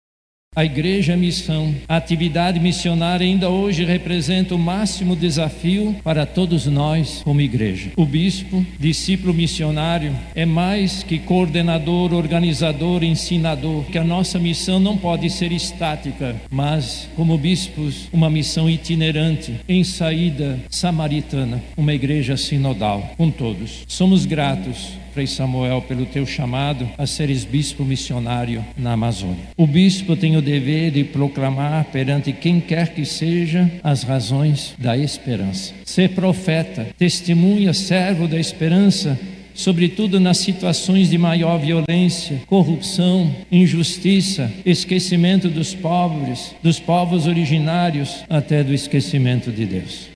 Durante a homilia, cardeal Steiner, reforçou a importância do seu serviço para a igreja da Amazônia, bem como o dever de proclamar perante quem quer que seja as razões da esperança cristã; lembrando o ano jubilar.
SONORA-2-CARDEAL.mp3